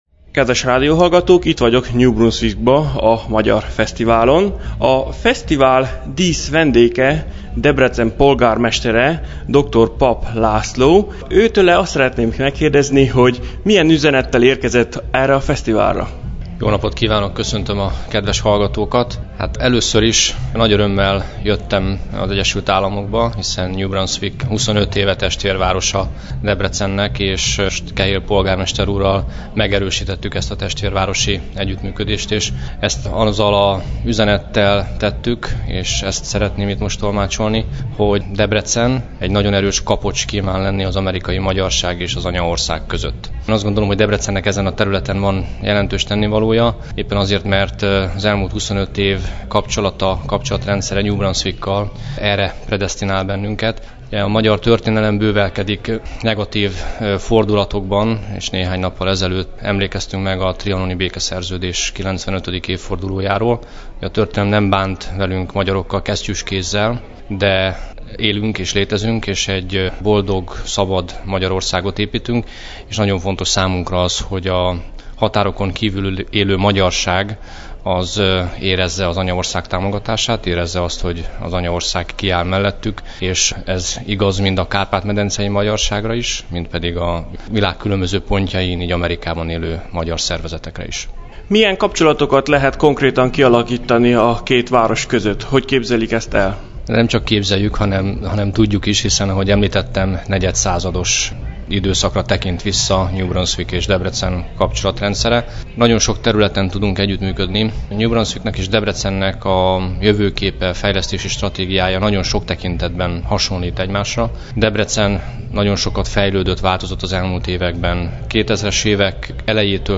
New Brunswick és Debrecen 25 éve testvérvárosi kapcsolatot tart fent, melyről a következő interjúban kértem a polgármester urat, hogy számoljon be.
DebreceniPolgarmester.mp3